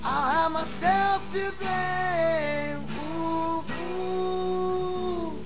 Sound bytes were captured from the vh-1 Special of Leif Garrett:  Behind The  Music and Where Are They Now.